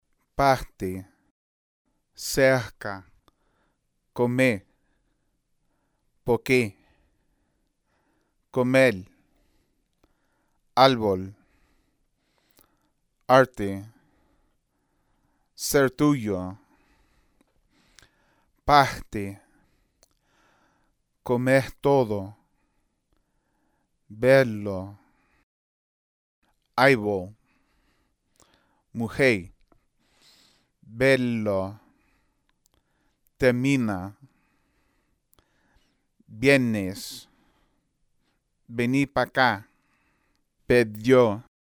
Variantes dialectales del archifonema /R/ ante consonantes.